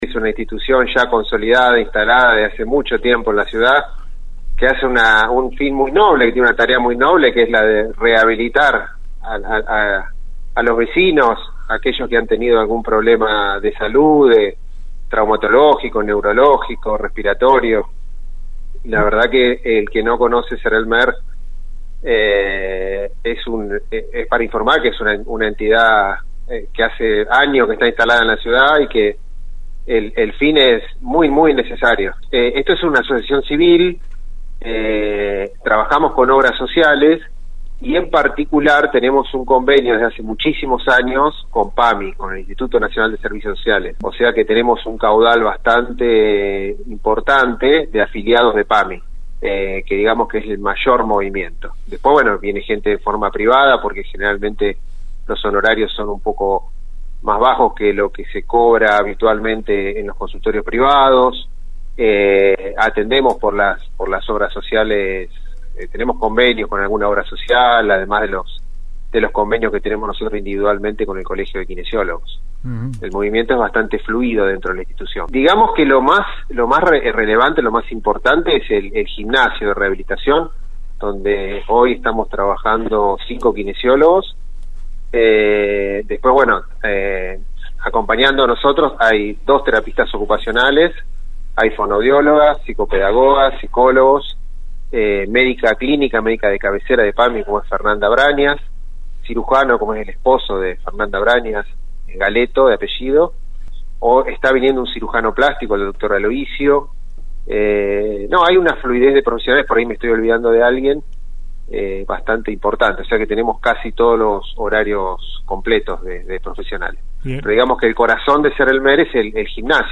EN RADIO UNIVERSO 93 1